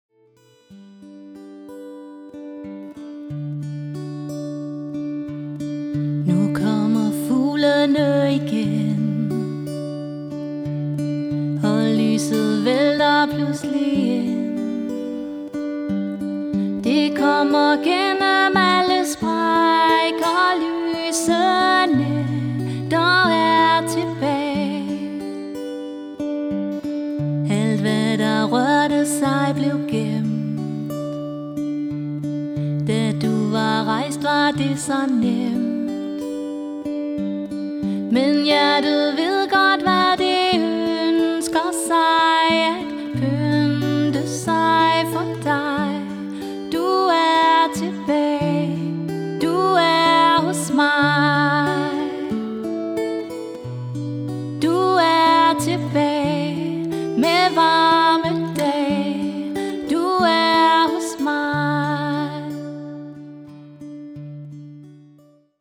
Sangerinde og solomusiker (sang og guitar)
Smukt, akustisk og rørende.
Jeg spiller guitar til og medbringer selv udstyr.